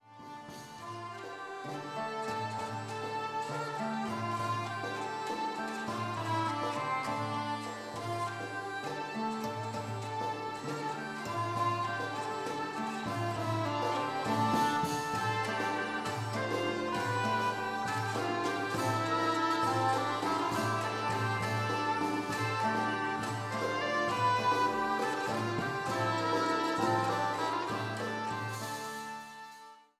Rondo